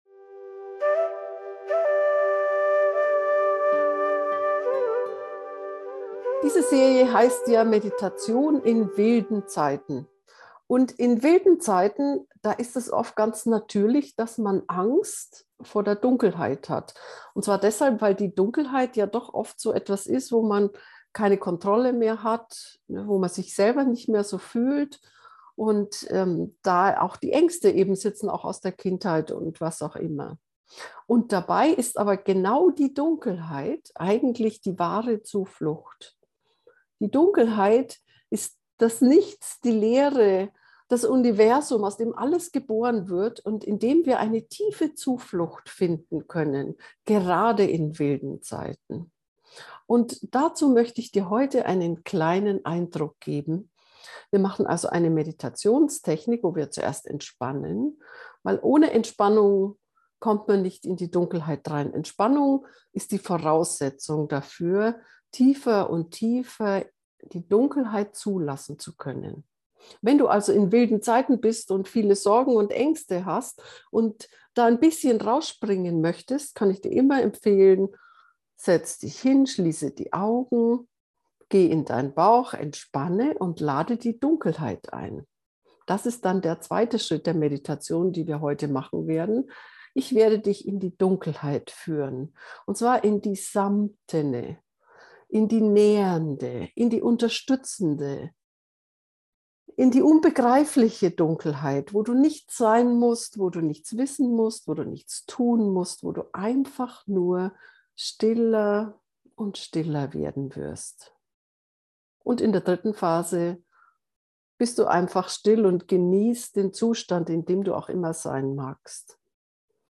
zuflucht-dunkelheit-gefuehrte-meditation